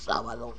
View ABSTRACT   The Spanish word "sábado" said by an esophageal speaker
Tech. description: Samplig rate: 22050, Bits-per-sample: 16, Mono